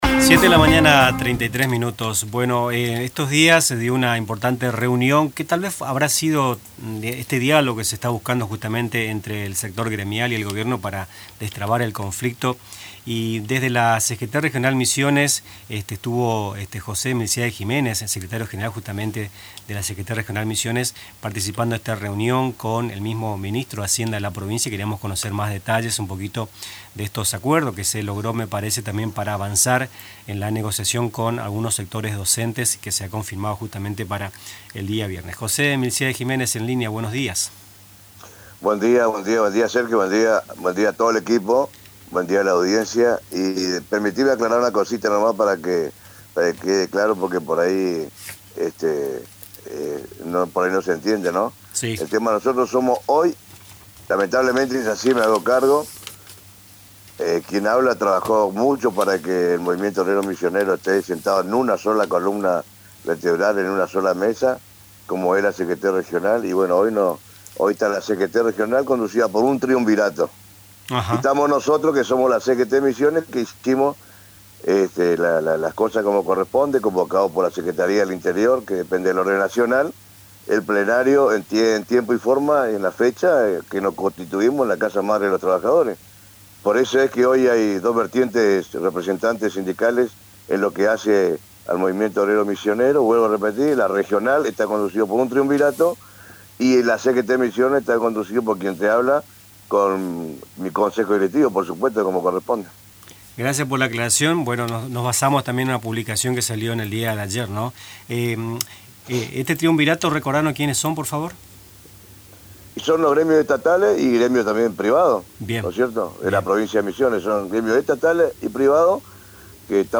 Durante una entrevista telefónica con Radio Tupa Mbae